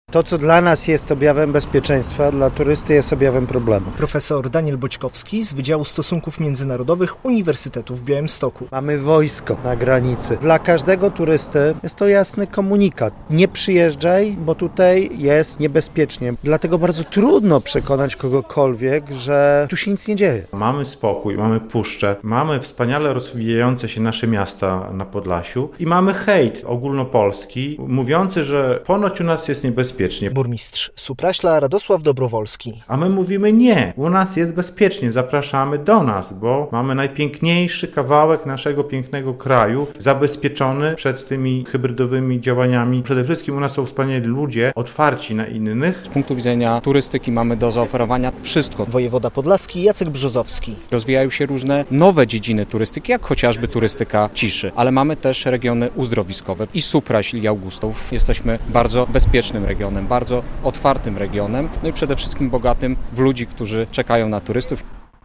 Do Supraśla zjechali burmistrzowie podlaskich miast, przedsiębiorcy, ale też przedstawiciele Uniwersytetu w Białymstoku.
relacja